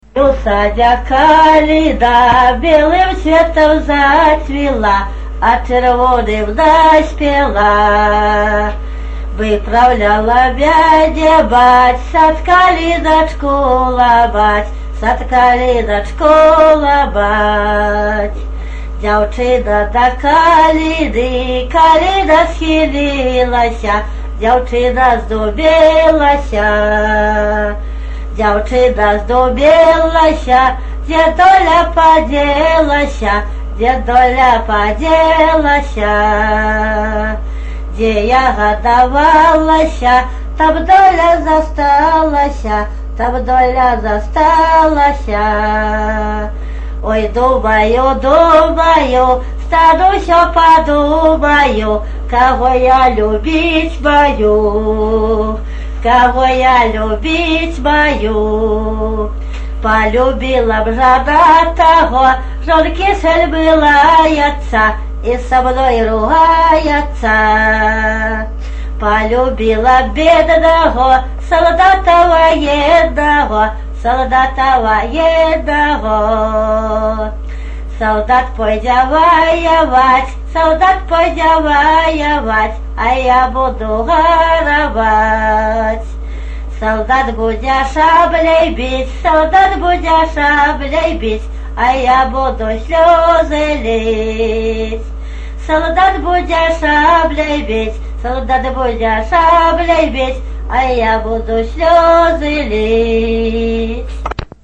Тема: ЭБ БГУ::Беларускі фальклор::Пазаабрадавая паэзія::любоўныя песні